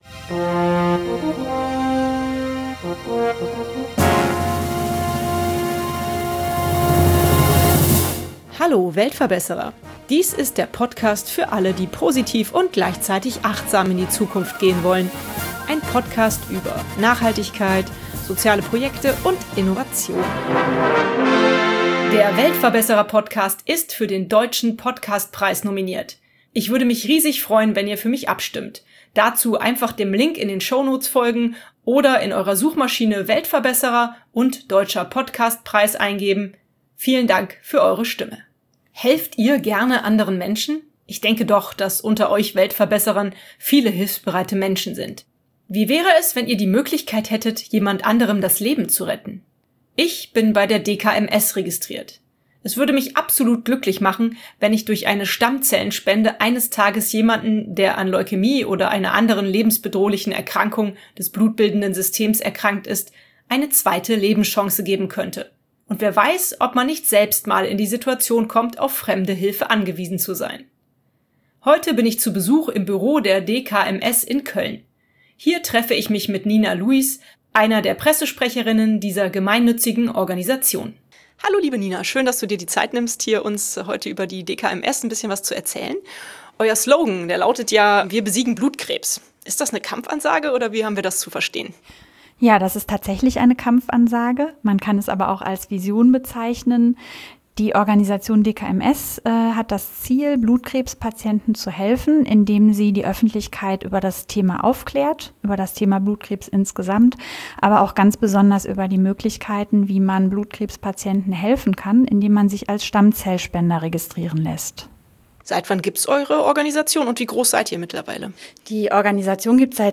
Heute bin ich zu Besuch im Büro der DKMS in Köln.